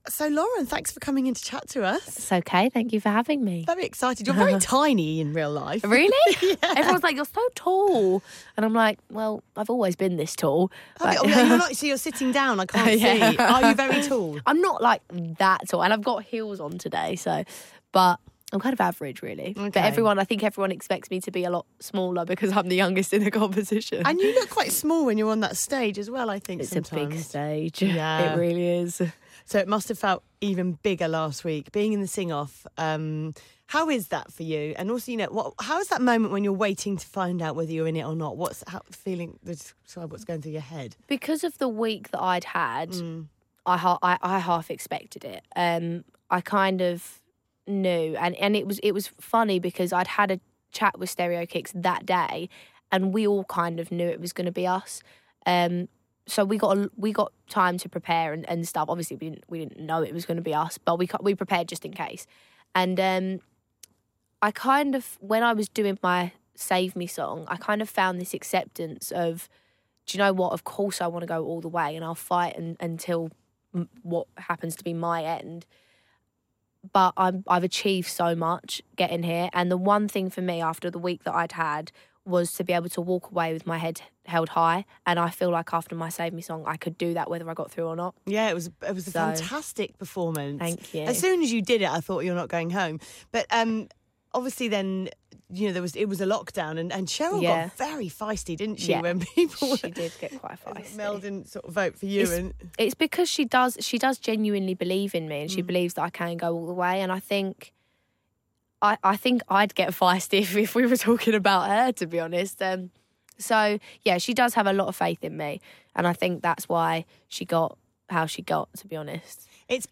The X Factor: Lauren Platt Interview